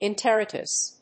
音節en・ter・i・tis 発音記号・読み方
/ènṭərάɪṭɪs(米国英語)/